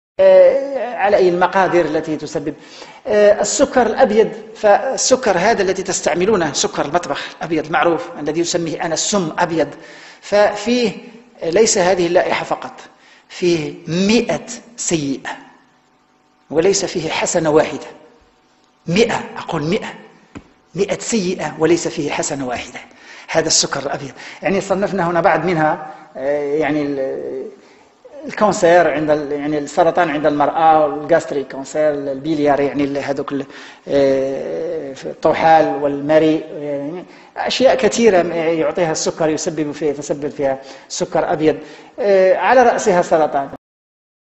اسمع ماذا يقول الدكتور محمد الفايد Dr Mohamed Elfaid عن  مخاطر السكر الابيض – Sugar